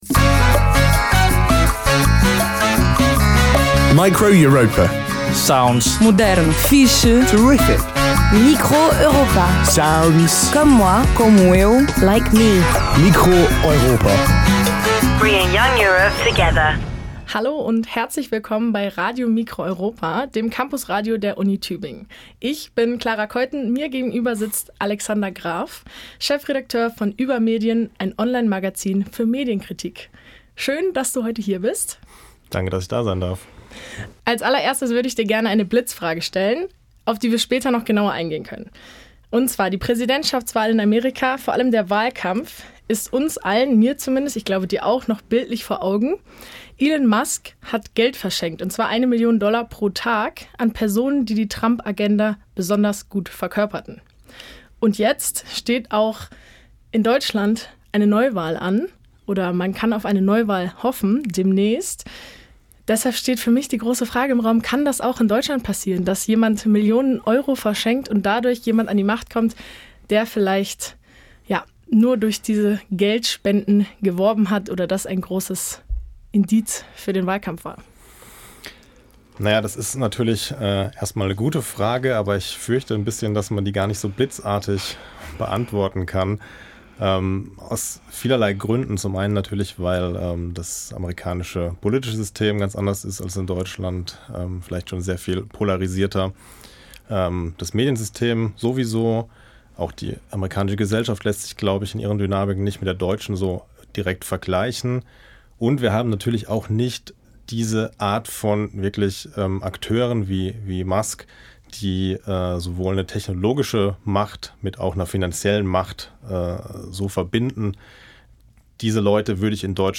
Studiogespräch
Live-Aufzeichnung, geschnitten